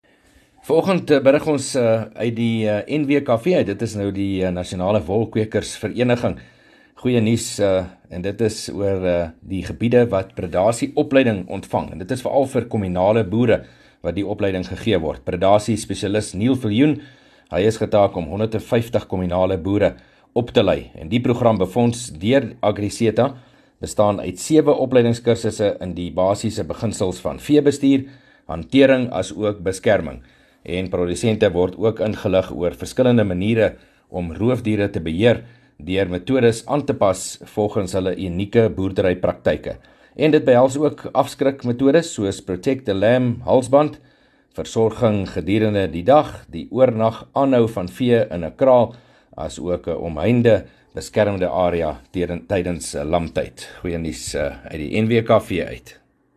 15 May PM berig oor die Nasionale Wolkwekersvereniging wat kommunale boere oplei